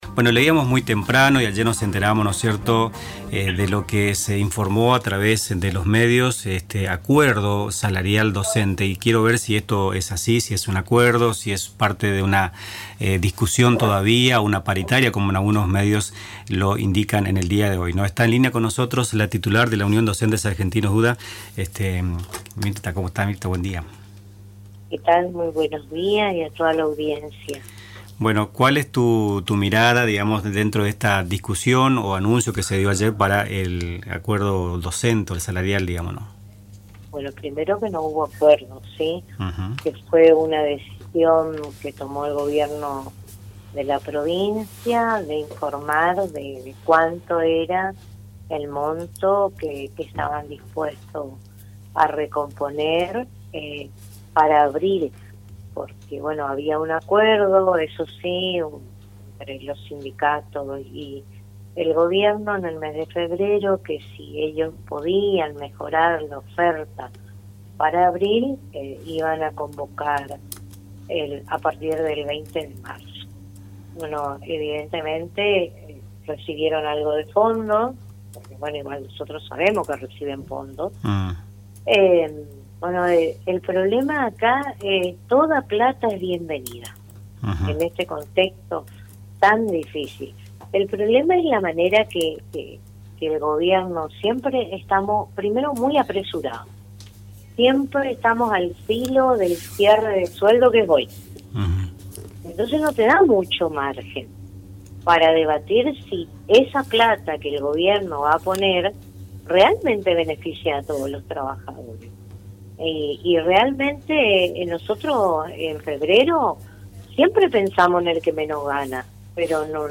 Escuchá la entrevista realizada en Radio Tupambaé